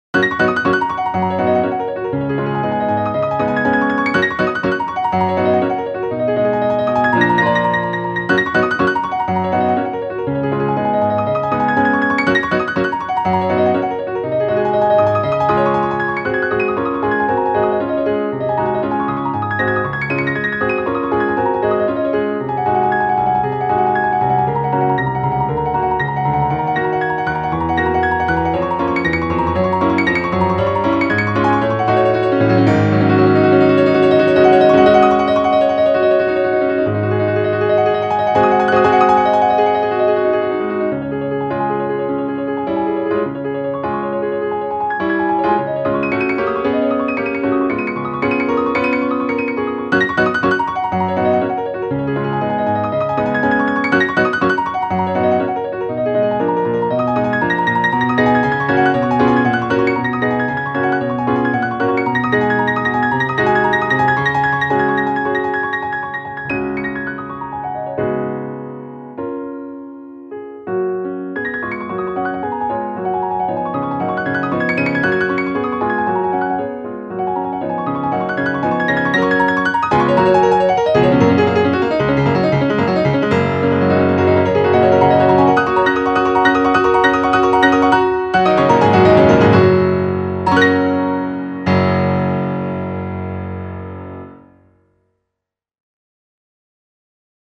♪サウンドプログラマ制作の高品質クラシックピアノ。